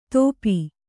♪ tōpi